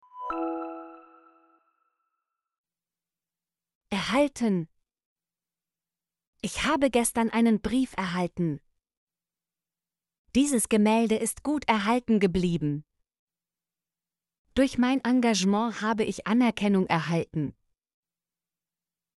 erhalten - Example Sentences & Pronunciation, German Frequency List